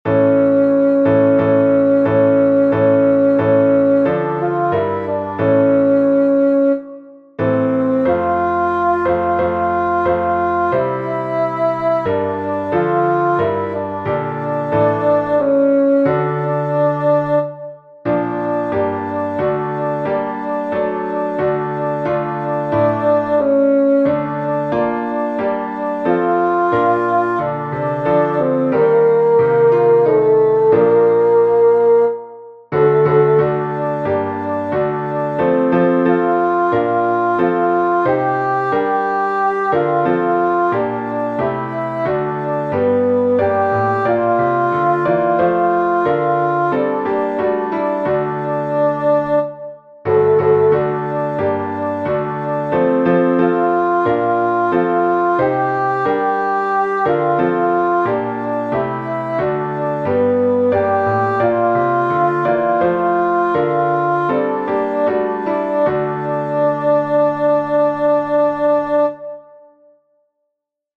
hide_not_thou_thy_face-alto.mp3